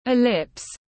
Hình ê-líp tiếng anh gọi là ellipse, phiên âm tiếng anh đọc là /iˈlɪps/.
Ellipse /iˈlɪps/